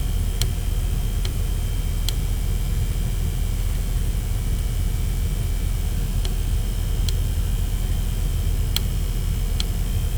Ich hab mich schon gefreut, doch dann habe ich mal ein Spiel gestartet (RDR2), und in diesem Lastszenario hatte ich plötzlich ein unregelmäßiges klackendes/tickendes Geräusch. Es hört sich ein bisschen an wie ein Relais das schaltet oder so. Es tritt absolut unregelmäßig auf, aber ist klar hörbar.
Nicht von dem Hintergrundrauschen irritieren lassen, das ist das Spulenfiepen der Grafikkarte, das auf der Aufnahme übermäßig zur Geltung kommt.
Anhänge ticking.wav ticking.wav 1,7 MB